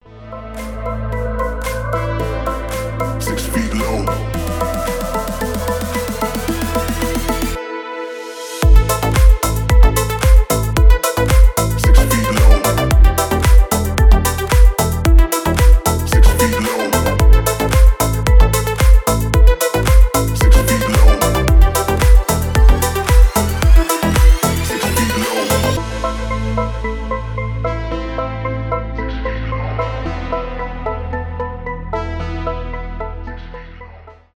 g-house